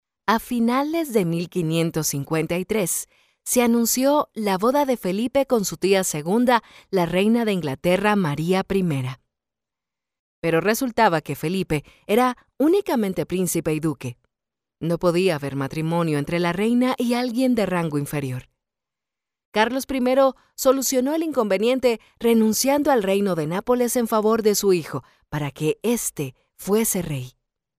Latin American female voice overs